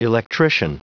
Prononciation du mot electrician en anglais (fichier audio)
Prononciation du mot : electrician